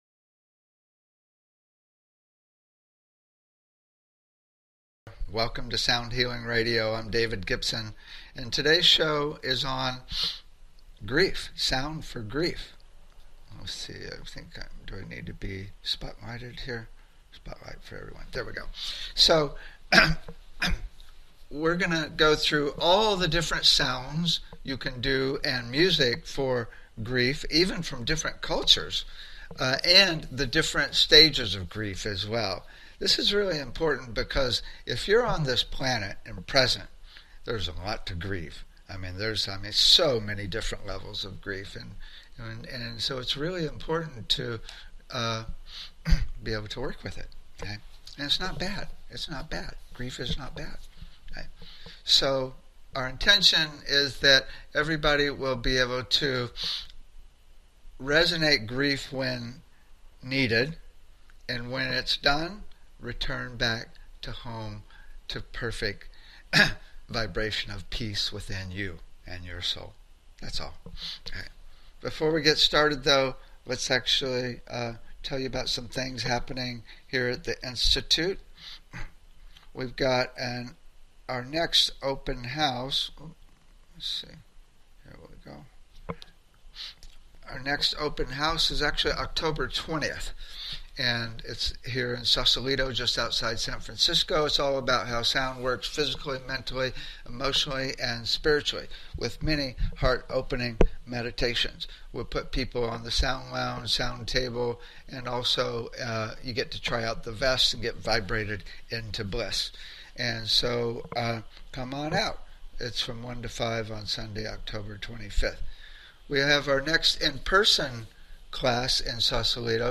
Talk Show Episode, Audio Podcast, Sound Healing and Sounds for Grief on , show guests , about Sounds for Grief, categorized as Education,Energy Healing,Sound Healing,Love & Relationships,Emotional Health and Freedom,Mental Health,Science,Self Help,Spiritual